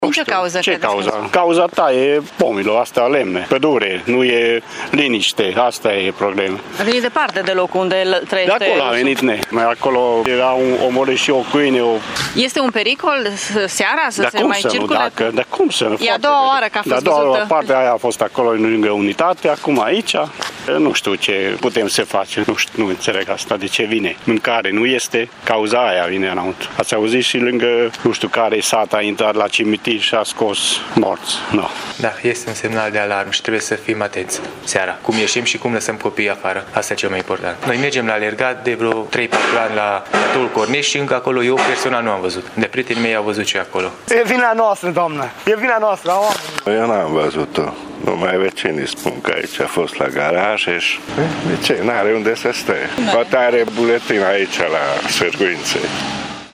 Oamenii din zonă sunt, însă, destul de speriați și singurul lucru pe care îl pot face este să evite ieșirea pe stradă sau în parcuri seara: